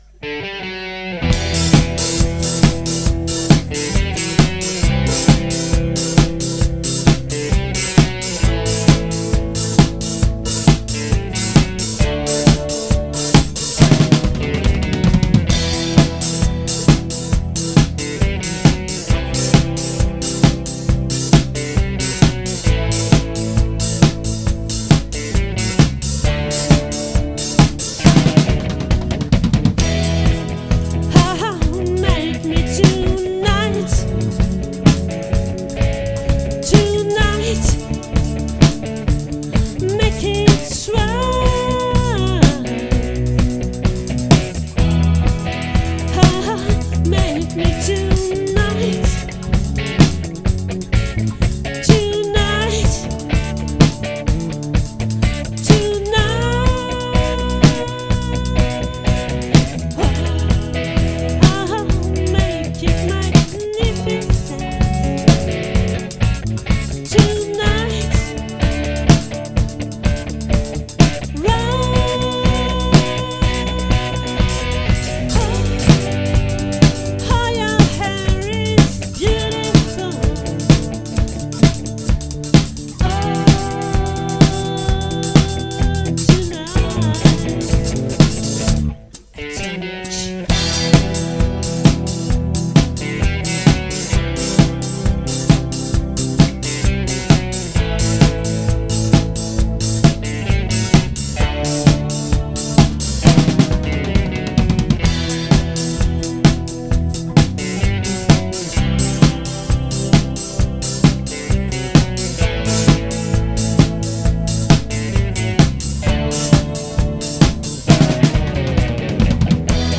🏠 Accueil Repetitions Records_2023_04_19